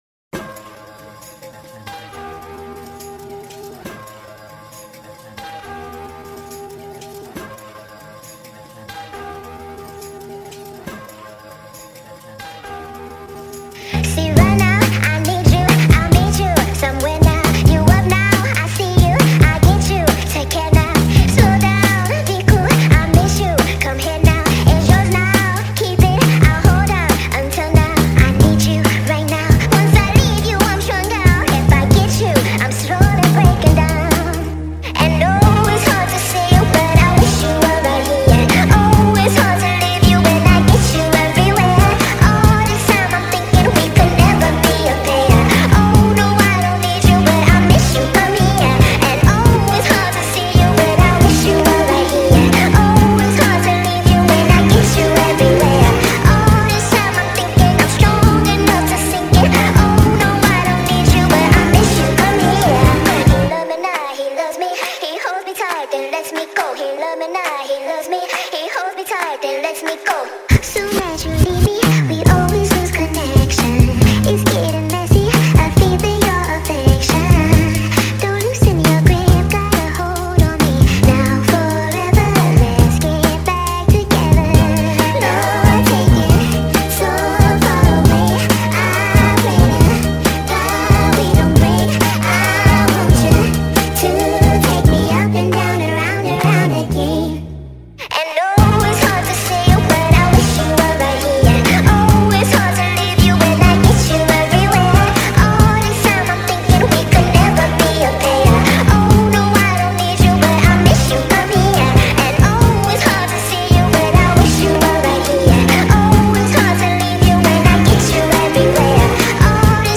نسخه سریع شده و Sped Up